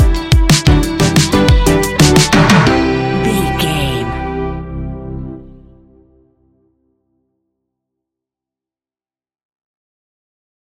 Ionian/Major
ambient
electronic
new age
chill out
downtempo
synth
pads
space music